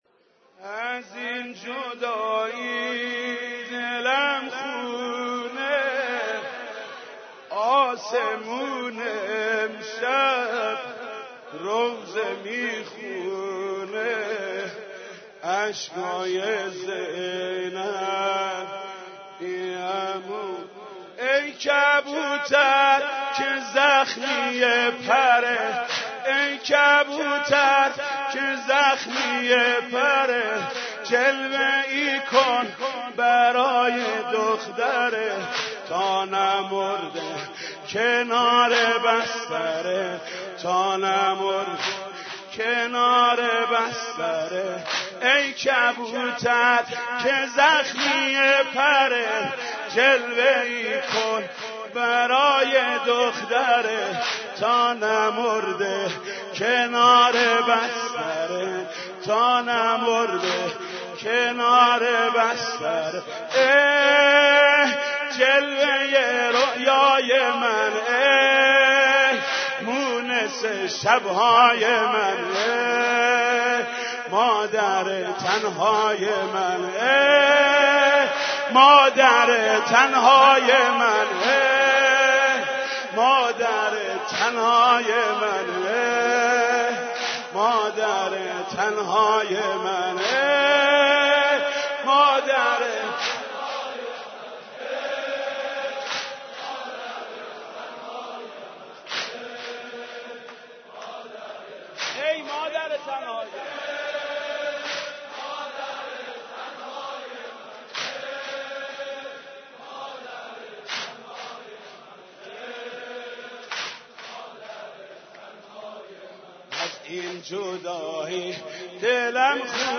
دانلود مداحی شهادت حضرت زهرا